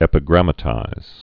(ĕpĭ-grămə-tīz)